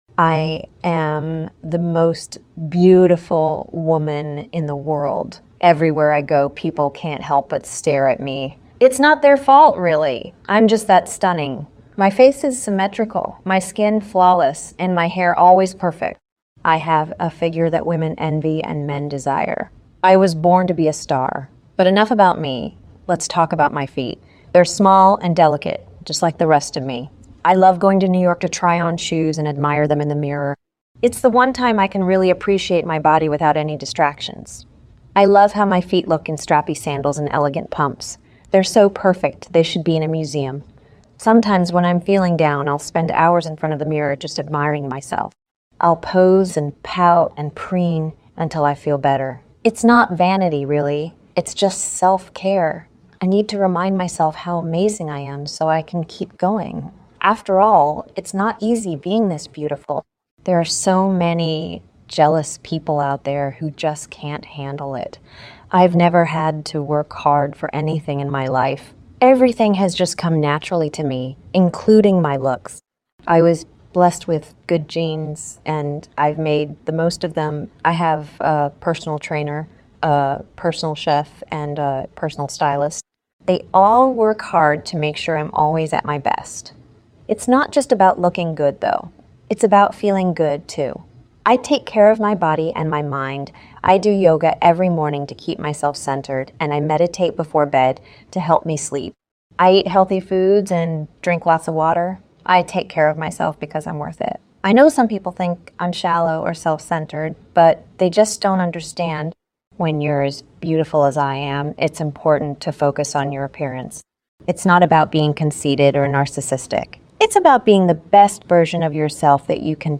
Anne Hathaway ( AI Voice sound effects free download